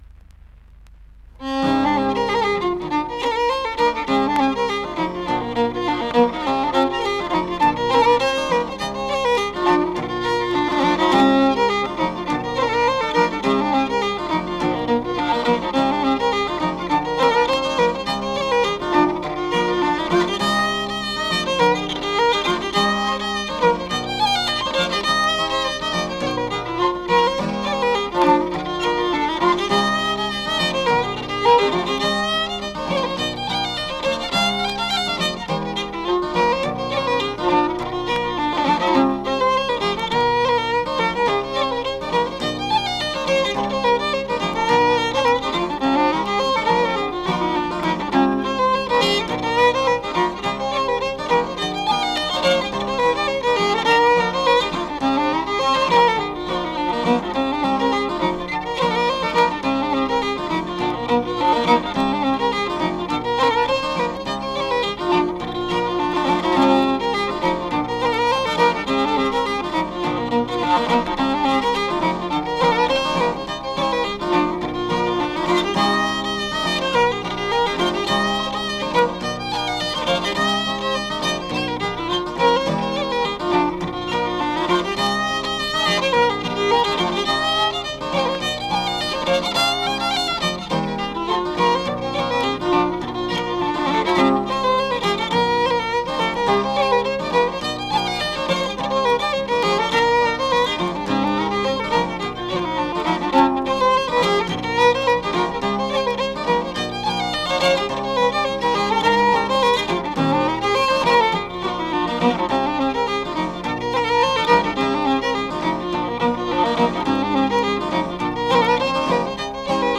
fiddle, flute
guitar
bodhrán